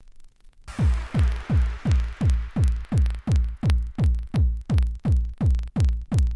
I have a full session of music and it has an annoying sound (hear glitch.mp3).
The noise is too loud for Audacity’s Noise Removal effect to get rid of it.
The session was recorded plugin the output channel of a sound mixer table to the line in of the soundcard.
That’s a weird click sound, it almost sounds like a multi-sample hi-hat or something.
Sounds to me like switching power supply noise or dimmer noise.
But you may be right, it’s pretty much with the beat, so could be a fault in the drum machine itself or a bad channel on the mixing board, or a bad cable …